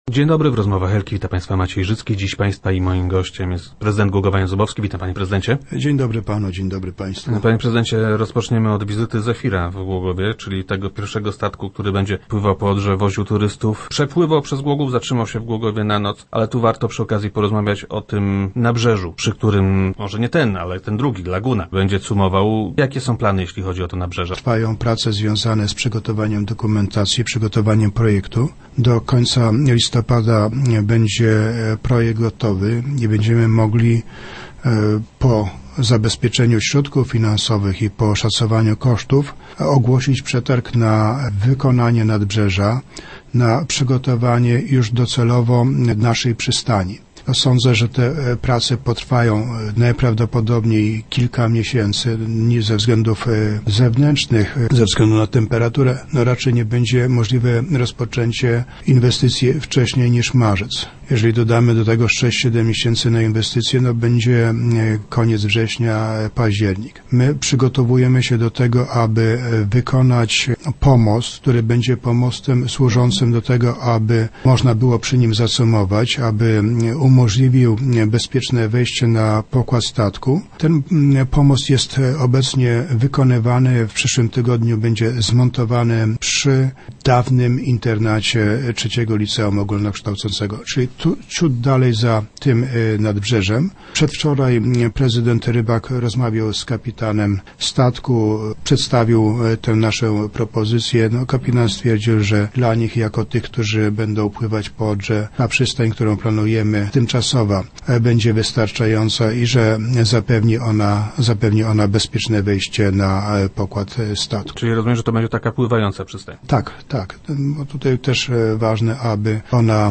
Na marinę z prawdziwego zdarzenia trzeba będzie jednak poczekać znacznie dłużej. - Tymczasowo statki będą przybijały do pomostu, który wkrótce zostanie zainstalowany – mówi prezydent Jan Zubowski.